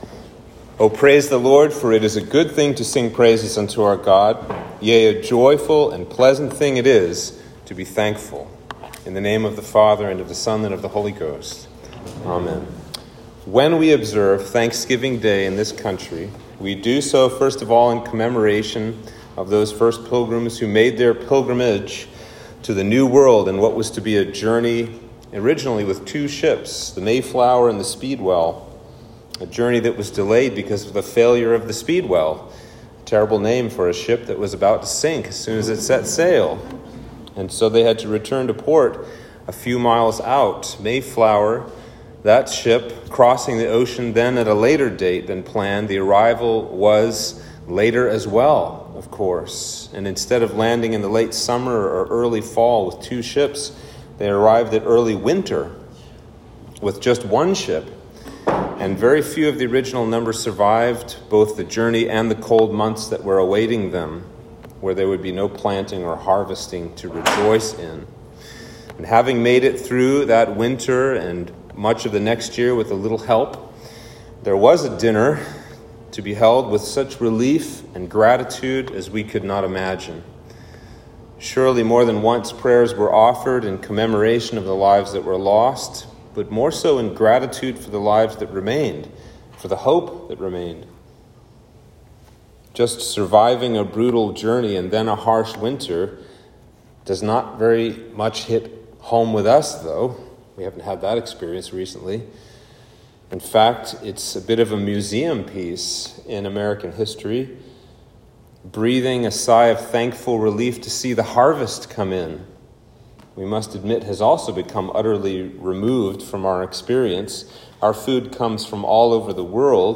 Sermon for Thanksgiving Day